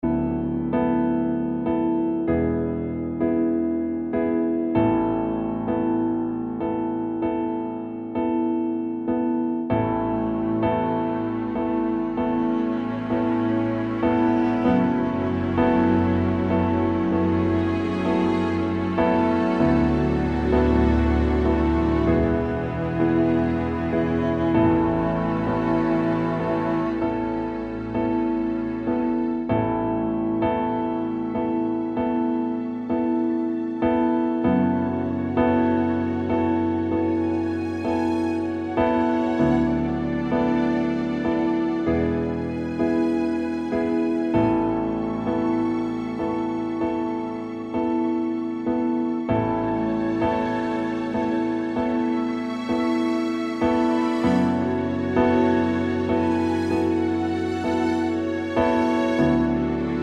Male Key